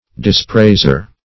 Dispraiser \Dis*prais"er\, n. One who blames or dispraises.